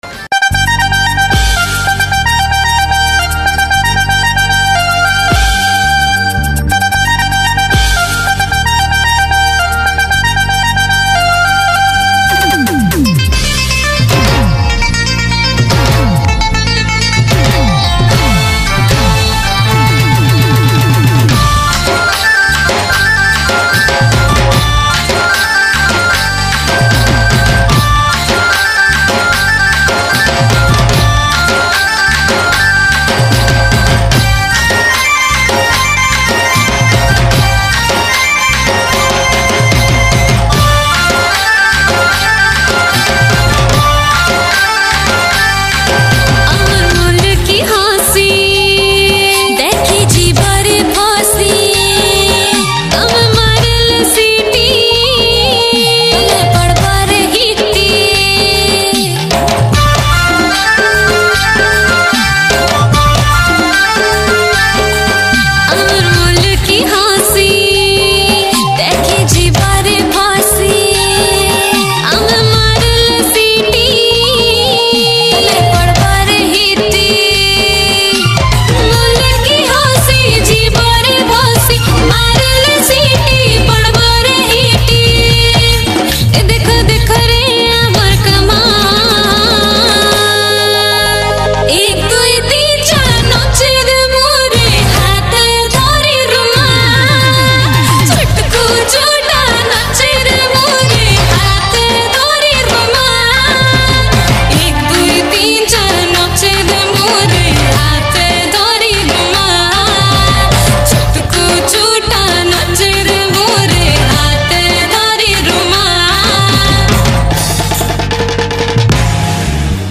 Category: New Sambalpuri Folk Songs 2022